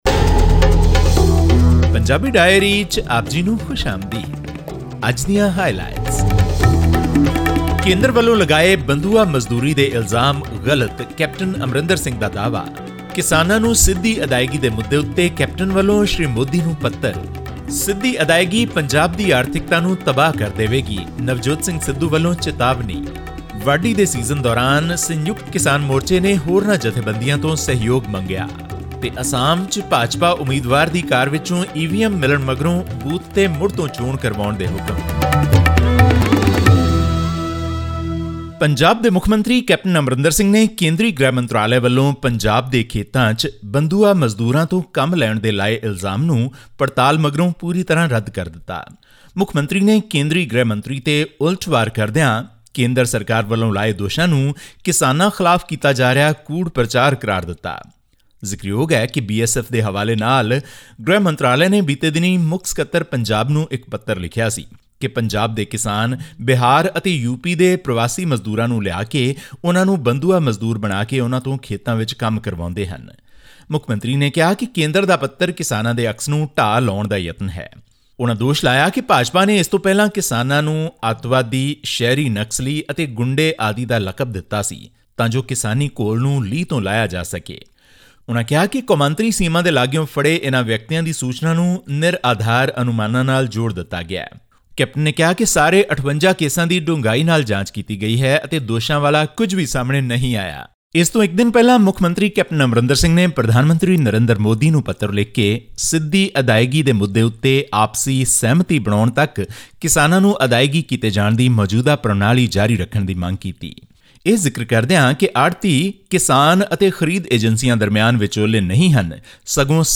Punjab Chief Minister Captain Amarinder Singh on Saturday wrote a letter to Indian Prime Minister Narendra Modi seeking continuance of the existing payment system to farmers for crop procurement until a consensus is reached on the issue of Direct Benefit Transfer (DBT). This and more in our weekly news segment from Punjab.